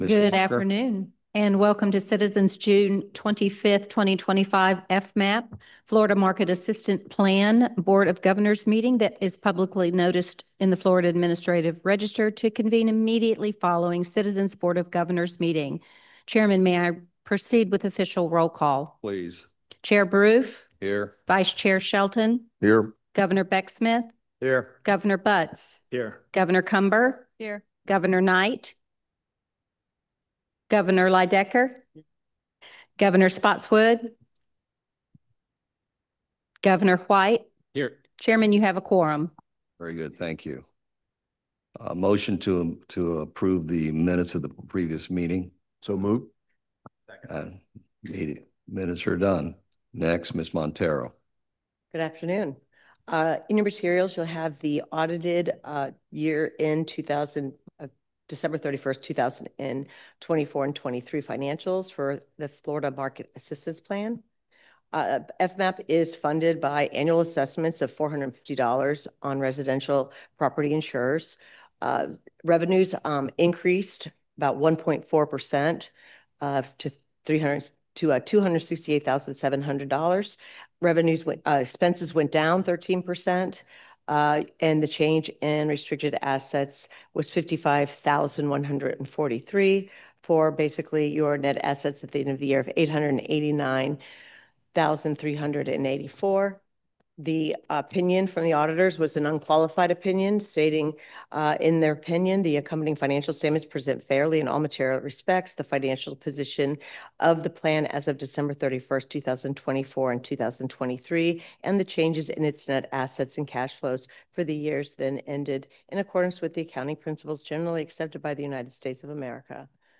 Zoom Webinar
The Westin Lake Mary